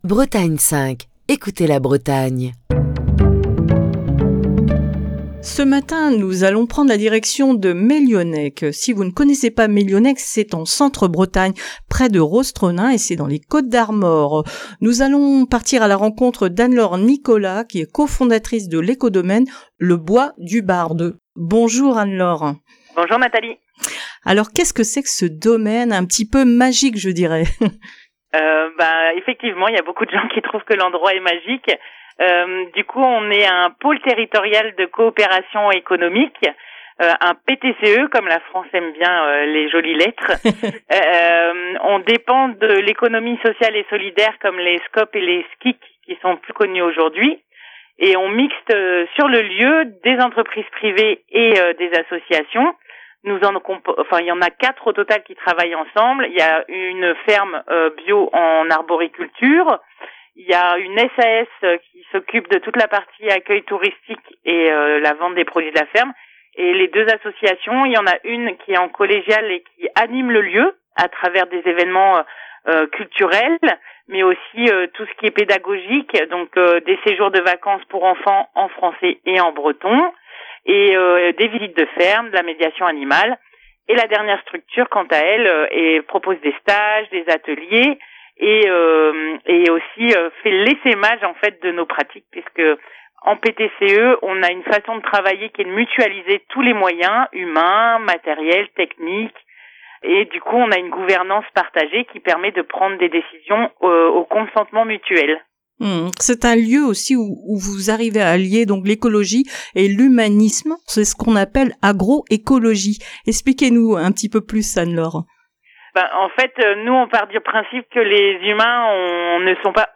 passe un coup de fil